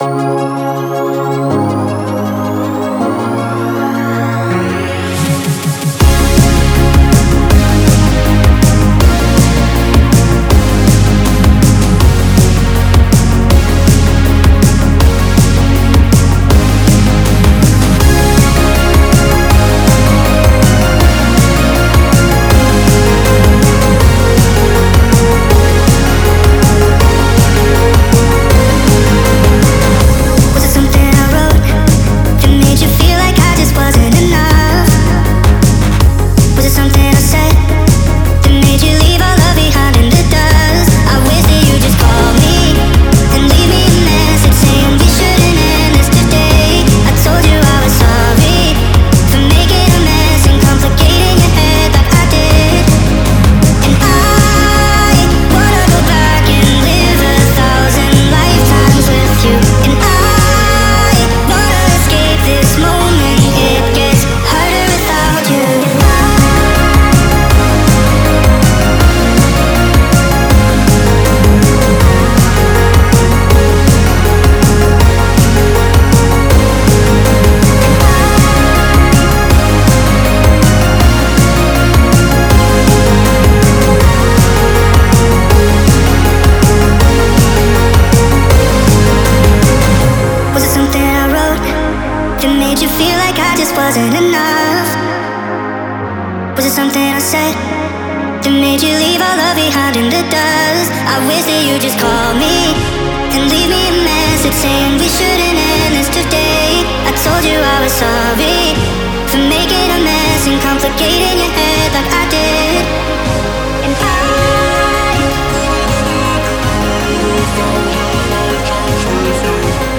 эмоциональная поп-баллада